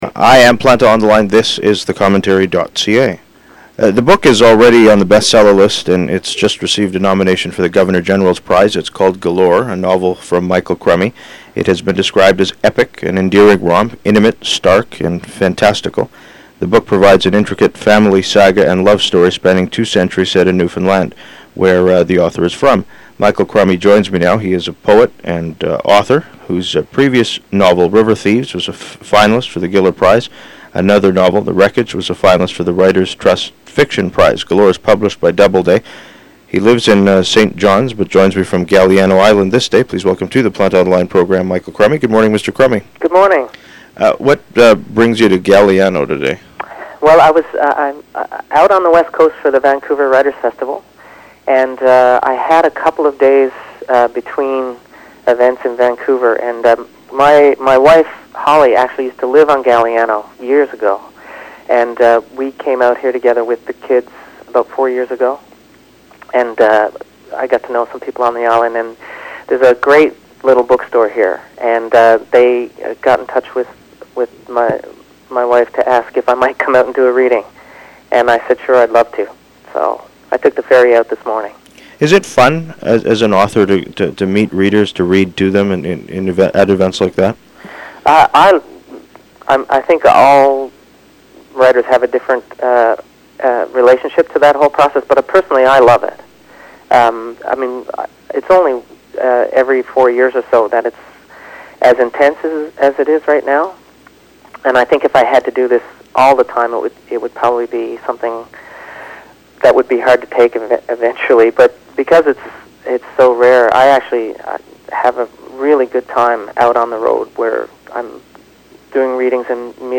He lives in St. John’s, but joins me from Galiano Island this day.